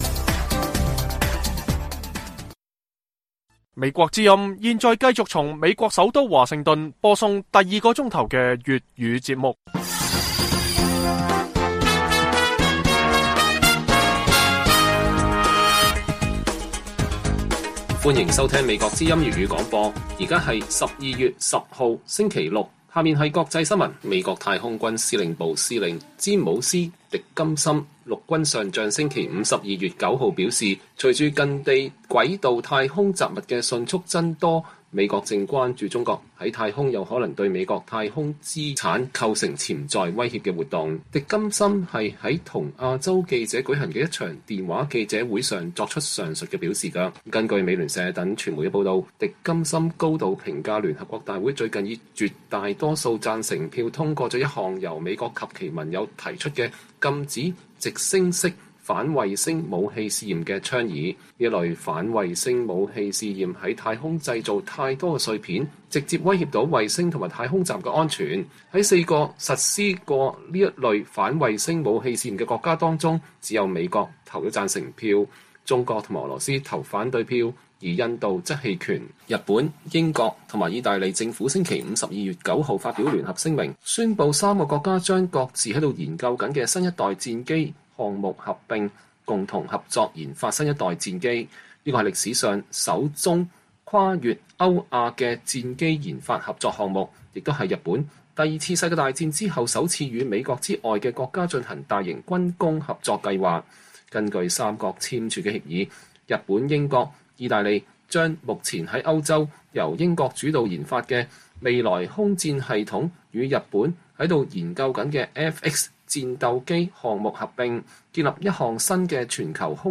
粵語新聞 晚上10-11點 : 中國放鬆防疫政策後 市民憂慮之餘更慶幸解壓